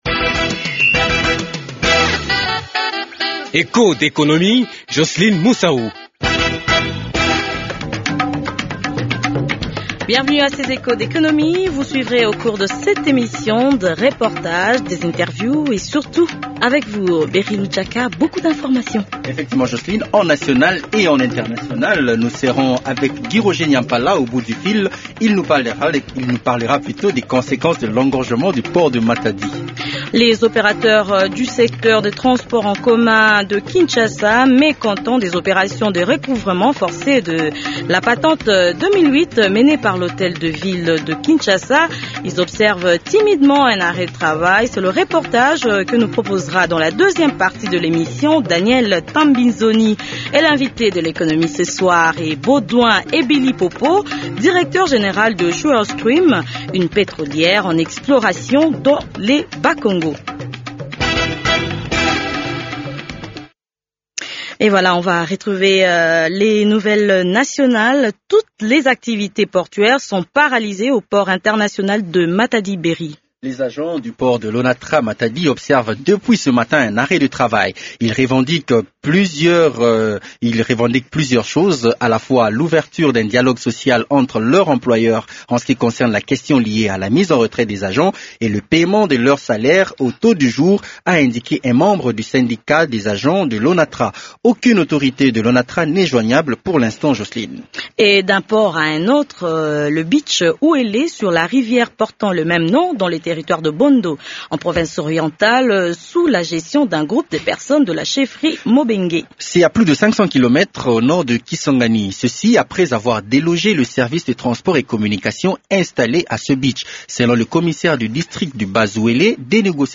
Il est l’invité du magazine Echos d’économie de ce soir. L’autre actualité c’est le recouvrement forcé de la patente 2008, ce qui cause une très forte difficulté de transport dans la capitale. Echos d’économie fait le tour de l’économie nationale et internationale dans ce numéro